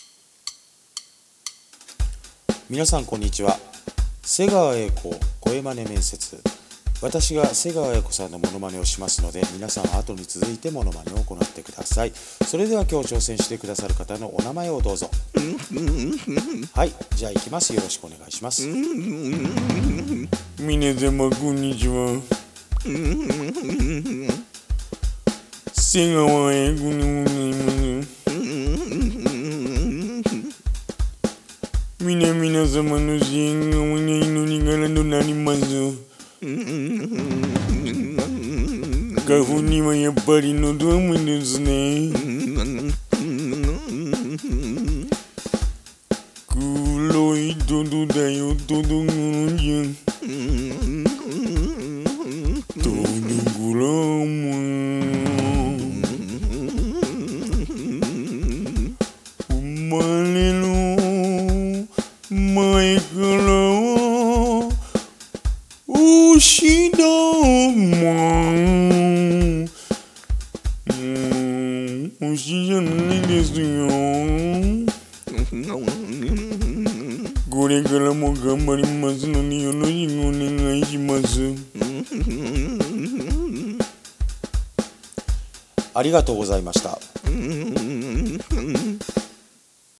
瀬川瑛子声マネ面接